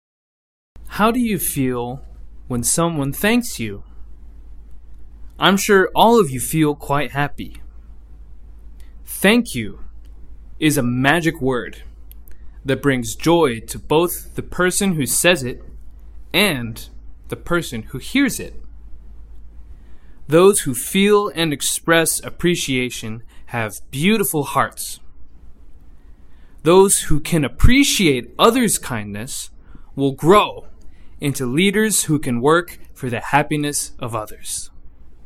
模範音声再生（通常版） 模範音声再生（通常版）